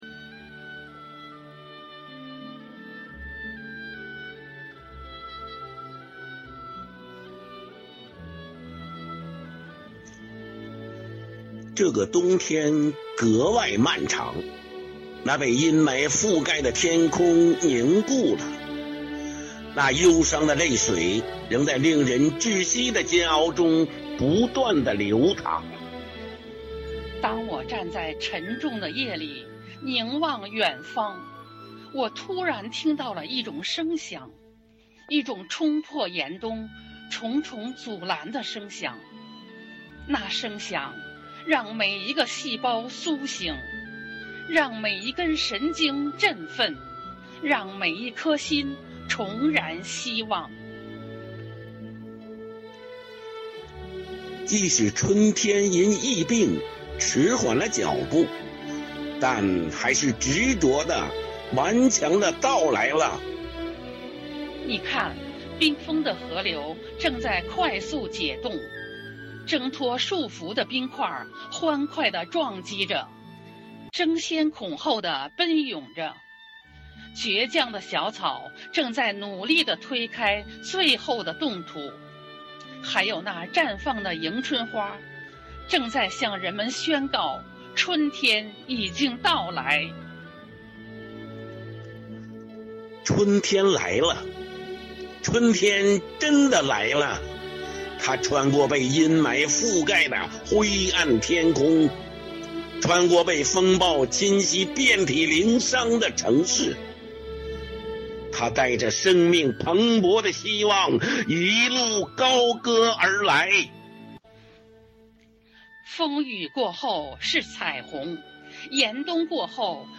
“四月，我们和春天在一起”主题云朗诵会
合诵《拥抱春天》
生活好课堂幸福志愿者八里庄西里朗读服务（支）队
《拥抱春天》合诵：八里庄西里朗读支队.mp3